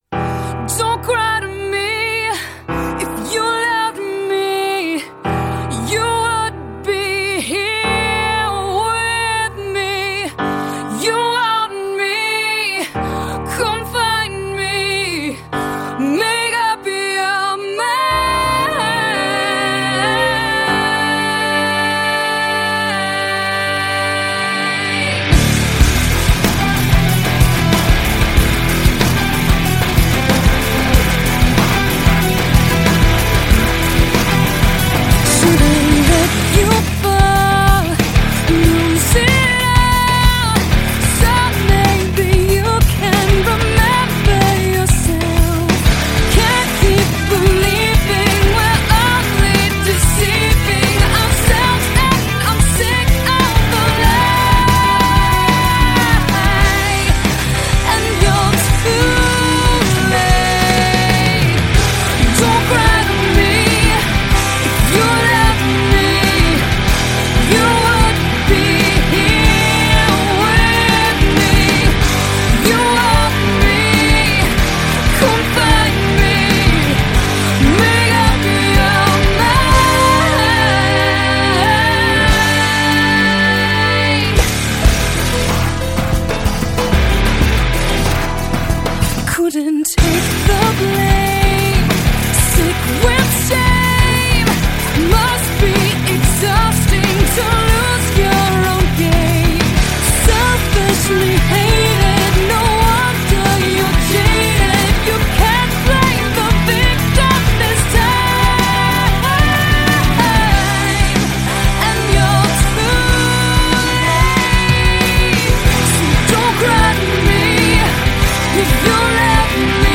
Жанр: Alternative, Gothic Metal